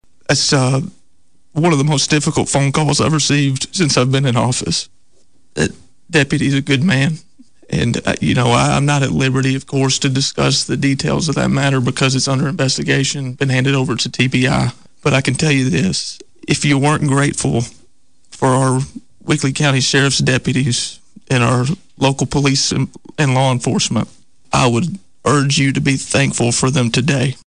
Mayor Hutcherson reminds us to be thankful for the dedicated members of Weakley County.